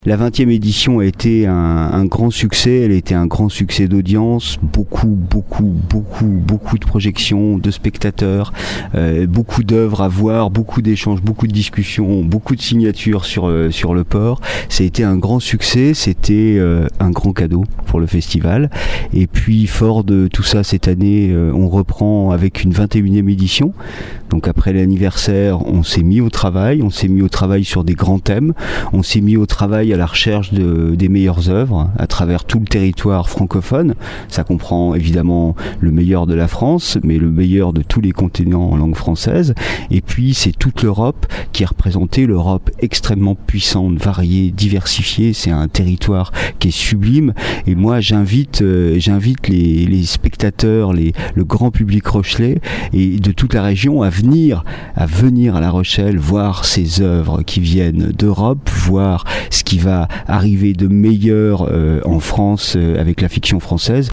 A noter qu’une quarantaine d’œuvres seront projetées. 15 prix seront remis.